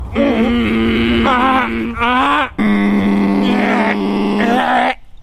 (vibrar telemovel)